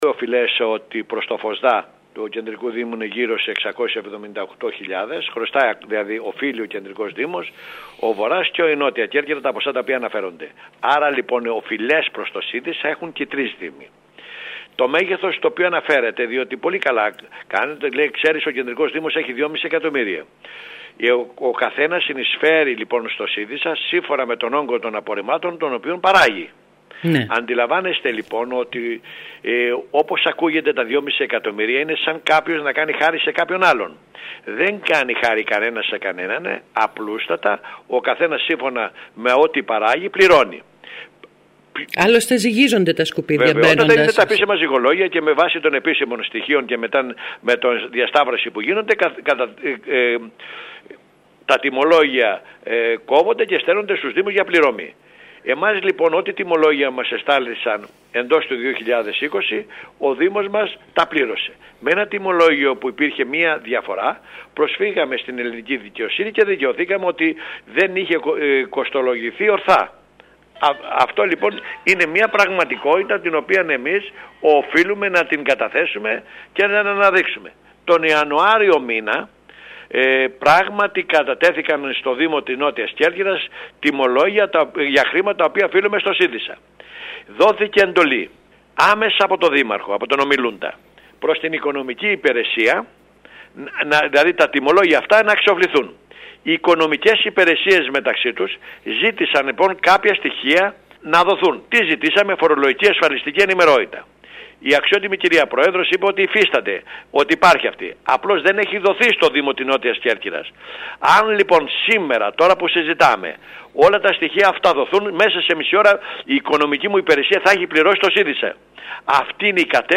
Τέλος ο κος Λέσσης μιλώντας σήμερα στην ΕΡΑ ΚΕΡΚΥΡΑΣ δήλωσε ότι δεν φταίει ο Δήμος του για την καθυστέρηση αλλά ο ίδιος ο ΣΥΔΙΣΑ ο οποίος μόλις πριν από λίγες μέρες έστειλε τα τιμολόγια του 2020.